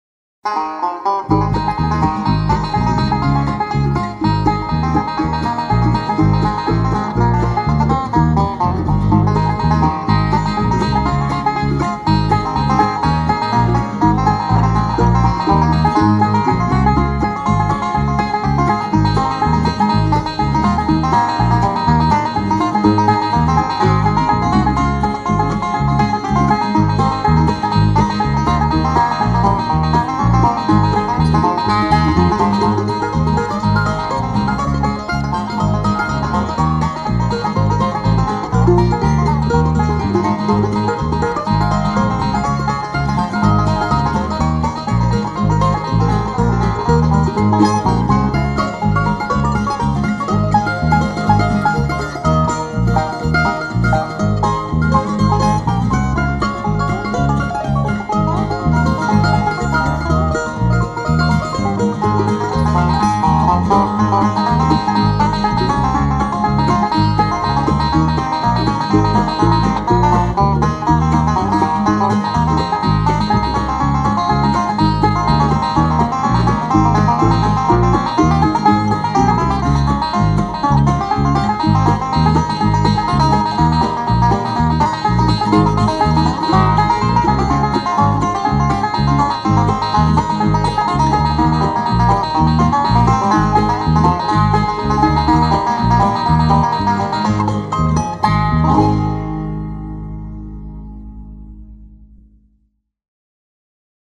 South Plains College  --  Levelland, TX